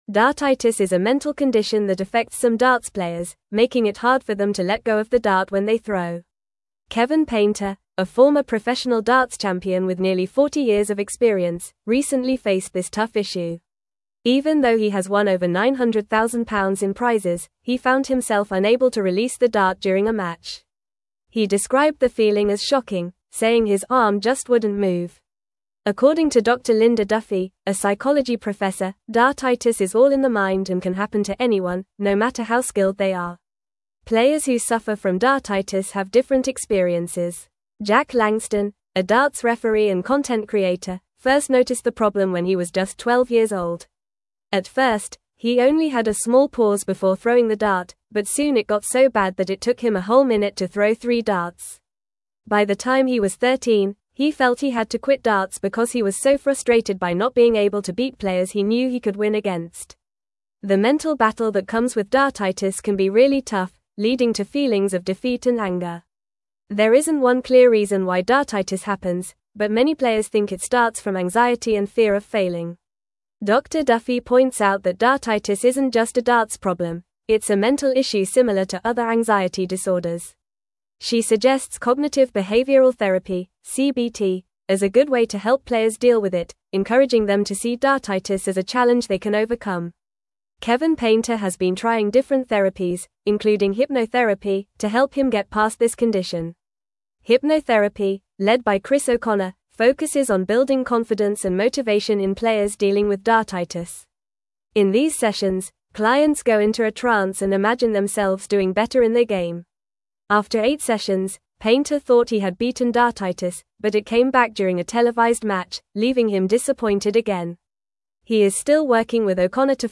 Fast
English-Newsroom-Upper-Intermediate-FAST-Reading-Overcoming-Dartitis-Athletes-Mental-Health-Challenges.mp3